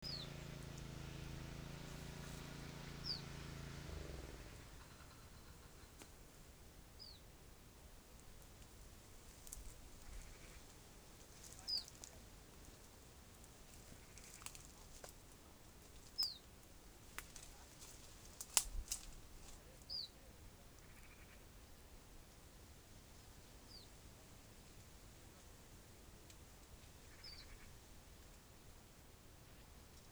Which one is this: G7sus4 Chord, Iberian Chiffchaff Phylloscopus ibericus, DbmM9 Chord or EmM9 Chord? Iberian Chiffchaff Phylloscopus ibericus